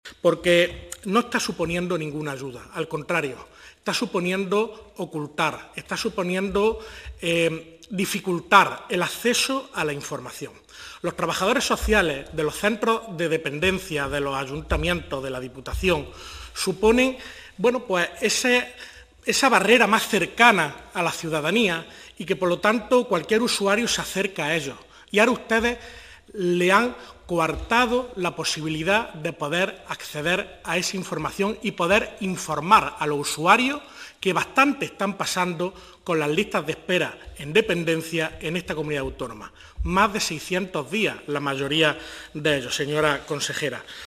El parlamentario socialista Víctor Torres evidenció en la Comisión de Inclusión Social, Juventud, Familias e Igualdad las consecuencias negativas que el decreto de simplificación de la Junta está ocasionando en la tramitación de la Dependencia. En vez de simplificar, está suponiendo una barrera para los usuarios, tal y como denuncian los trabajadores sociales e incluso el Colegio de Trabajo Social.